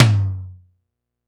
Tom Mid.wav